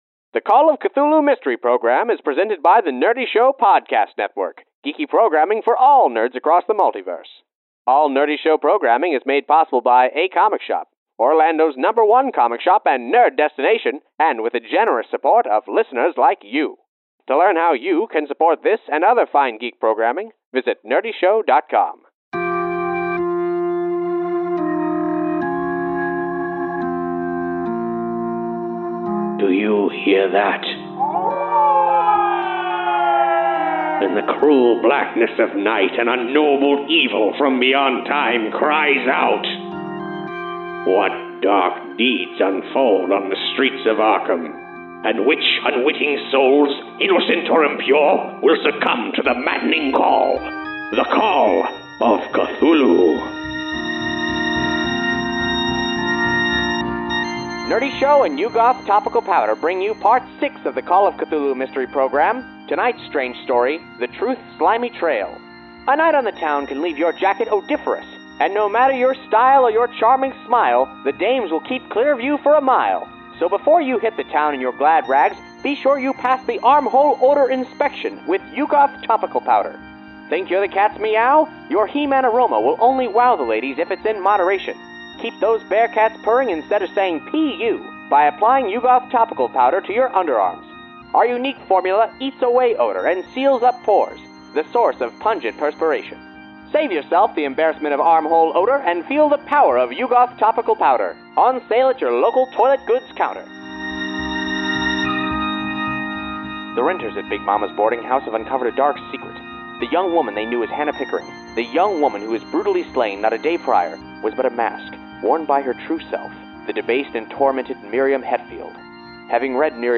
The Call of Cthulhu Mystery Program is live tabletop roleplaying turned into a 1930s radio serial.